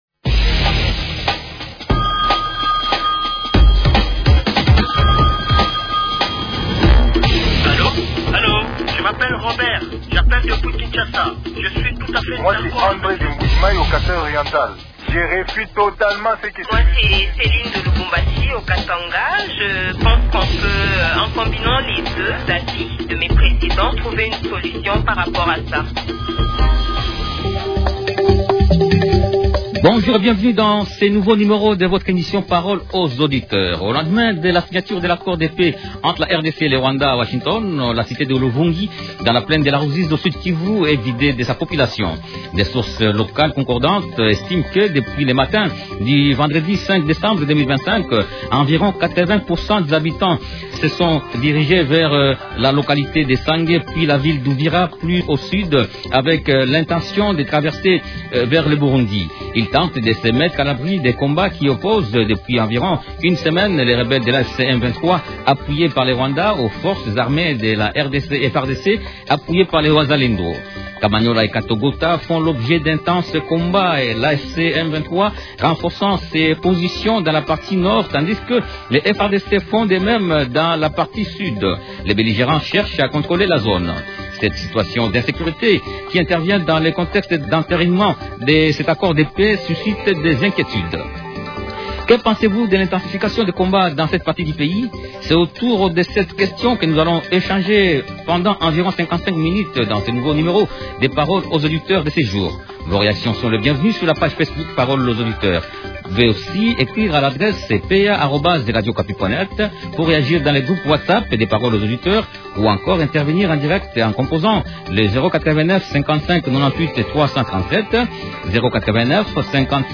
-Que pensez-vous de l’intensification des combats dans cette partie du pays ? Invité: Justin Bitakwira, ministre honoraire, il est député national élu d’Uvira au Sud-Kivu.